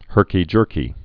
(hûrkē-jûrkē)